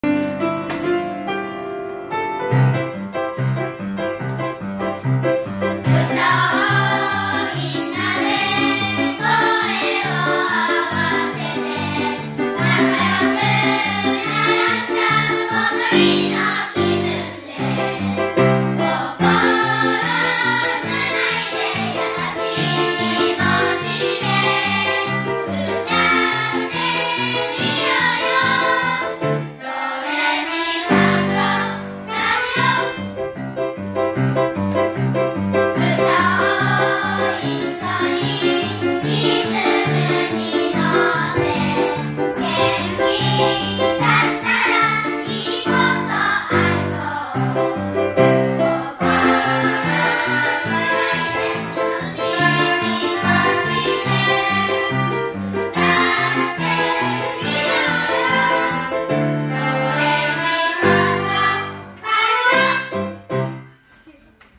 ♪いいことありそう♪4年生